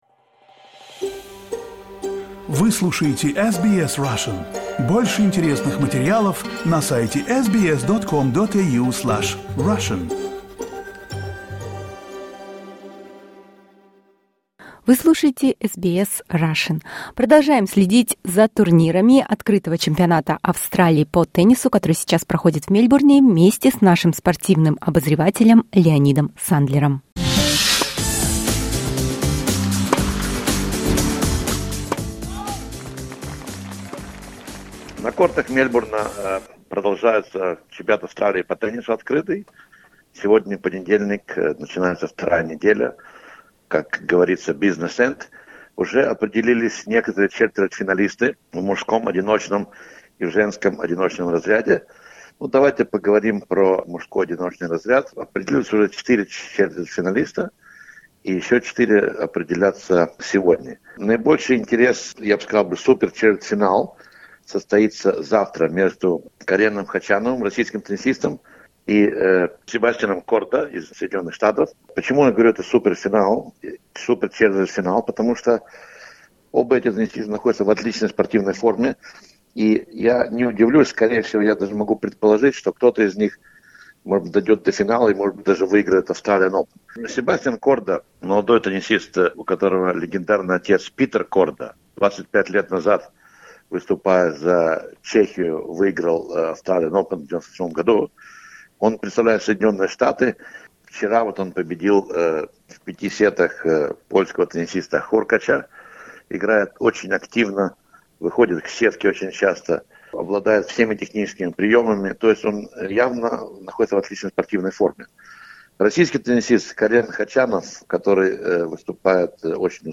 Our sports commentator from Melbourne